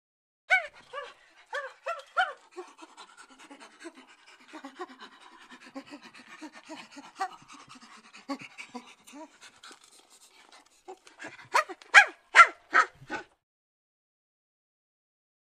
Monkey ( Unknown ) Panting. Heavy Panting, Whines And Barks. Close Perspective.